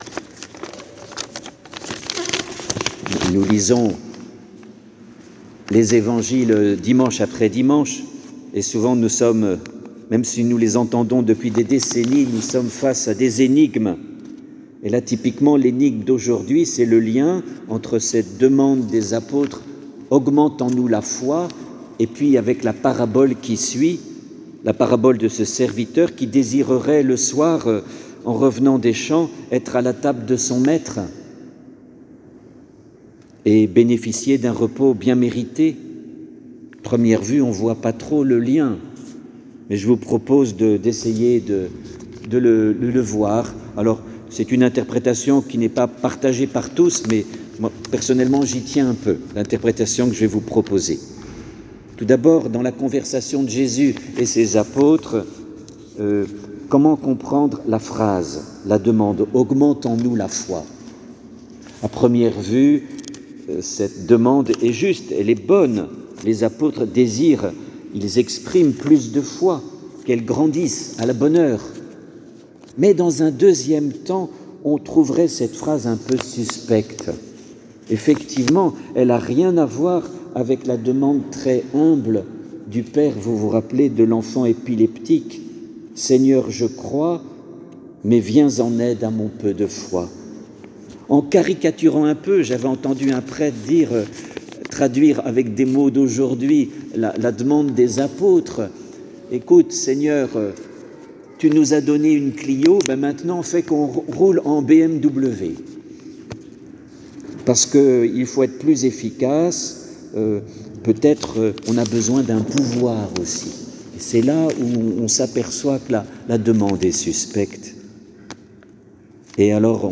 Eglise Saint Ignace